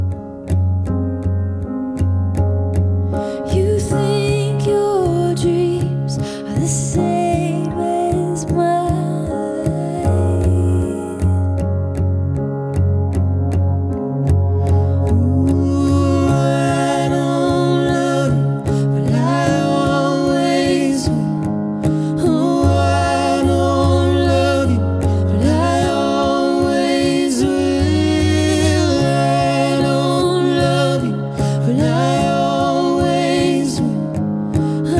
Жанр: Рок / Альтернатива / Кантри